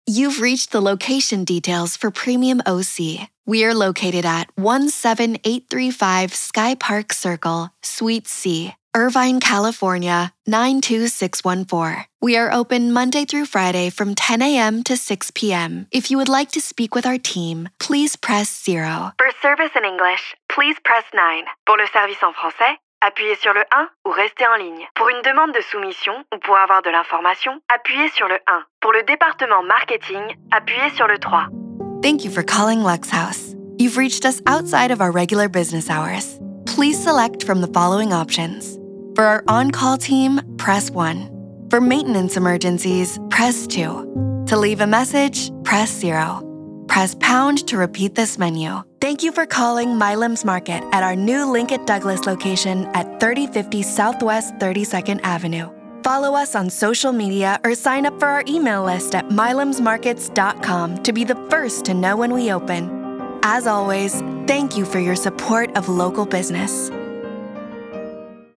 A young, fresh, feminine and soft voice with genuine warmth and clarity
IVR/Phone Reel